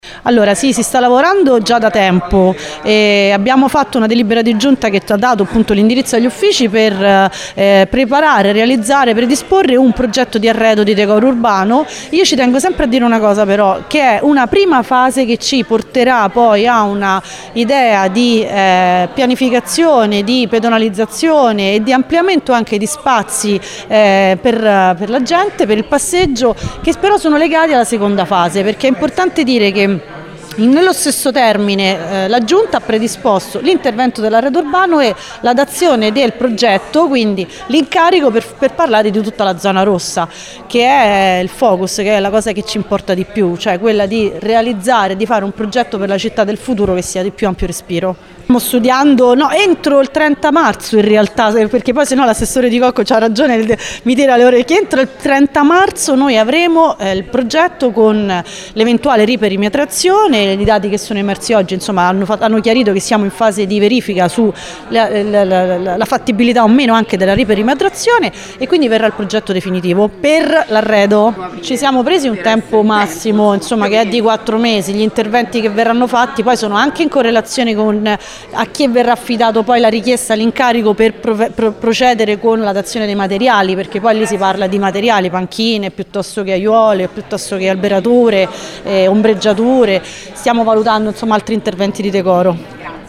Per vedere fisicamente gli arredi ci vorrà probabilmente l’estate, forse l’autunno: “Ci siamo dati quattro mesi di tempo dalla fine della sperimentazione per realizzarla”, conferma l’assessora all’urbanistica Annalisa Muzio.